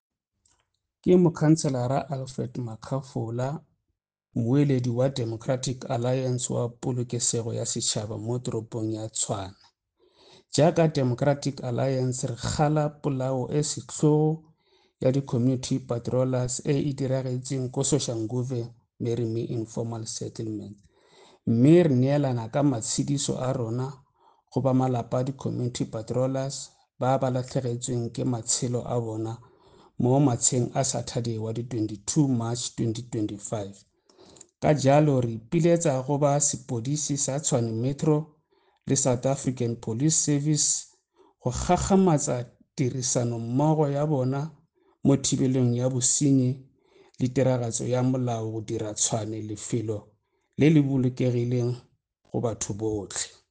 Note to Editors: Please find the attached soundbites in